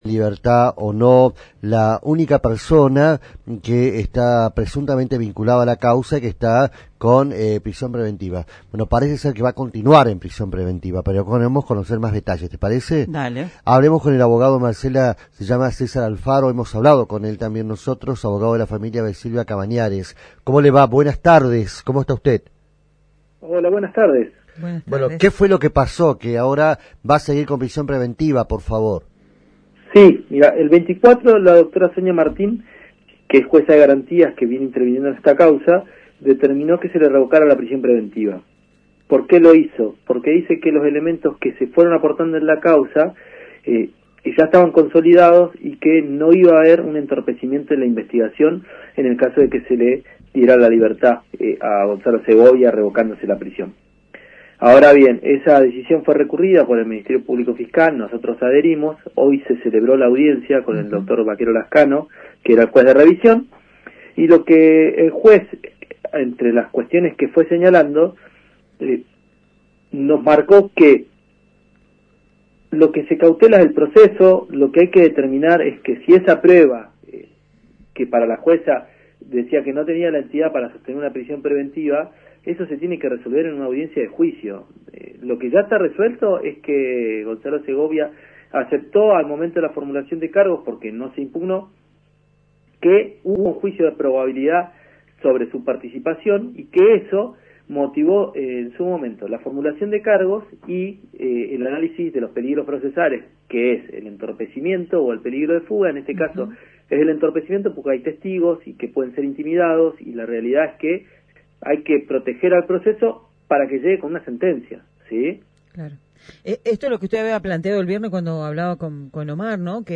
Escuchá la entrevista completa al abogado de la familia